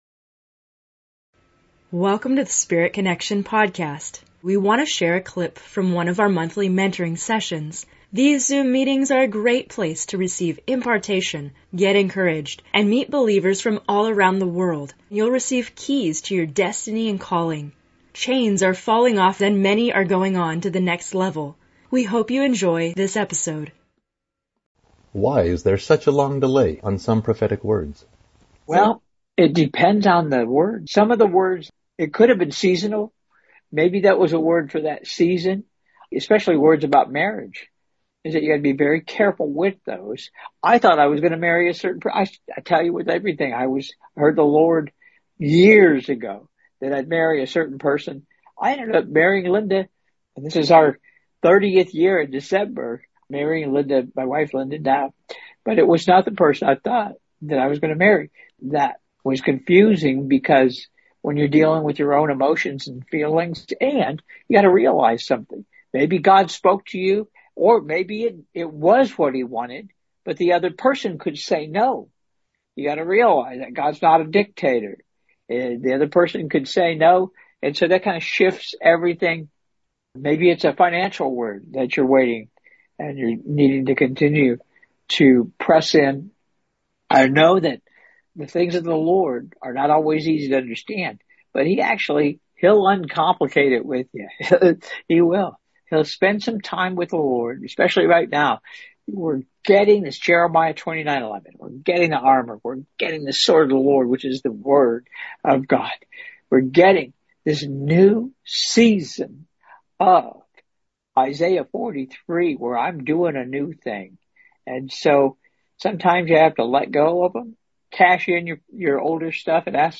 In this episode of Spirit Connection, we have a special excerpt from the latest Monthly Mentoring Session.